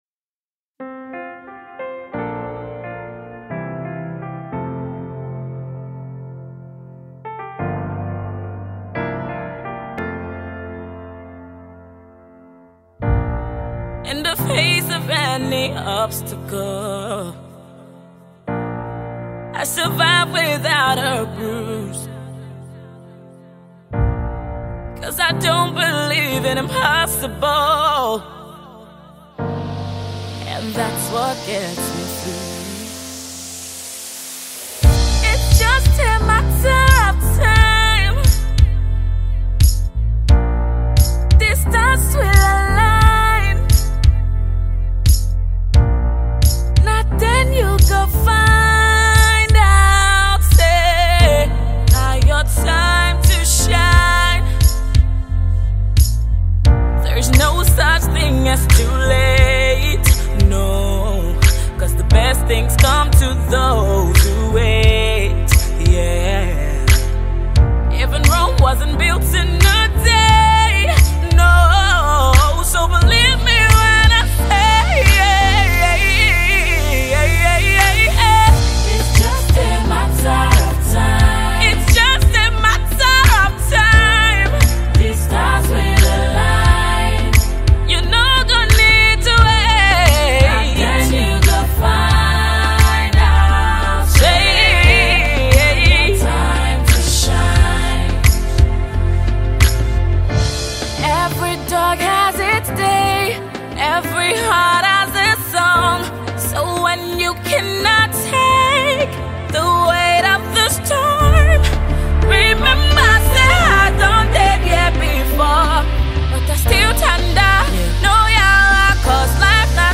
A mix of soul, Afro-pop and RnB.